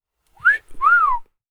wolf-whistle.wav